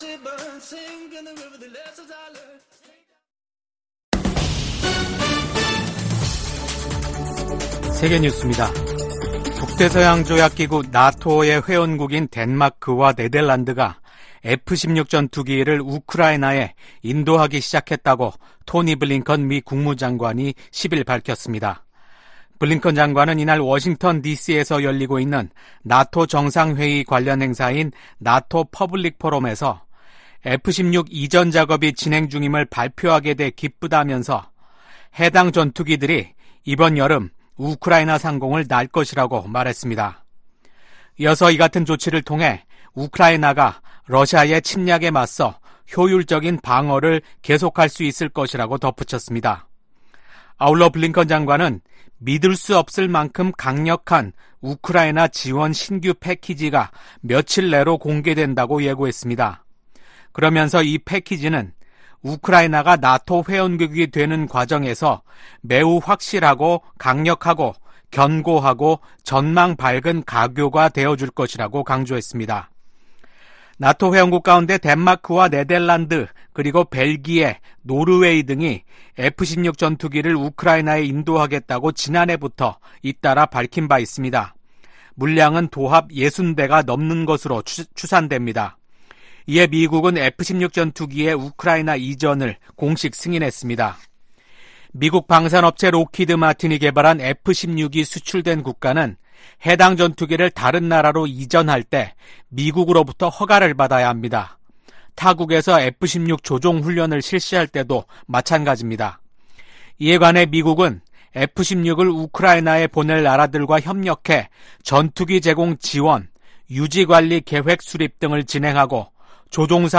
VOA 한국어 아침 뉴스 프로그램 '워싱턴 뉴스 광장' 2024년 7월 11일 방송입니다. 워싱턴에서 북대서양조약기구(NATO∙나토) 정상회의가 개막한 가운데 조 바이든 미국 대통령 러시아의 침략 전쟁은 실패했다고 지적했습니다. 미국 북 핵 수석대표인 정 박 대북고위관리가 최근 사임했다고 국무부가 밝혔습니다. 탈북민들이 미국 의회에서 열린 증언 행사에서 북한 주민들이 세상을 제대로 알고 변화를 주도하도록 외부 정보 유입을 강화해야 한다고 촉구했습니다.